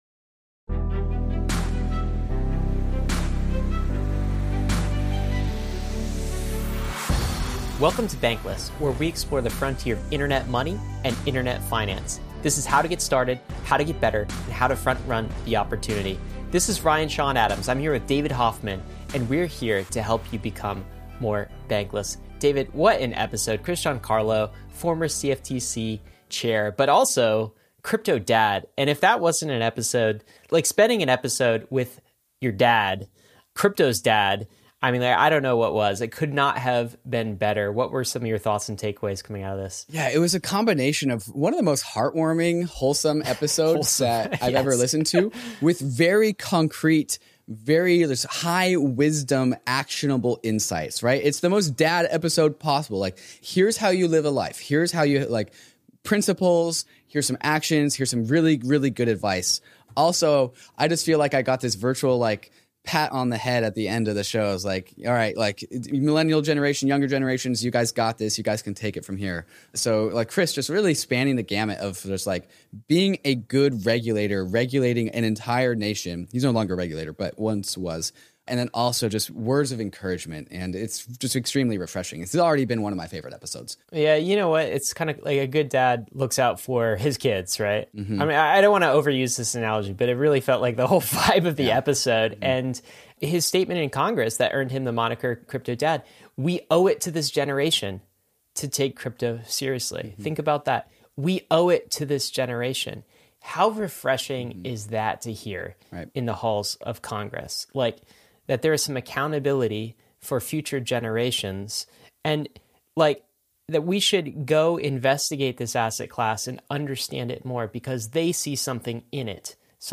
This conversation circles around many of the topics Chris covers in his new book, 'The Fight for the Future of Money.' We move through the specifics like the differences between Futures and Spot ETFs, but then we turn towards the big picture.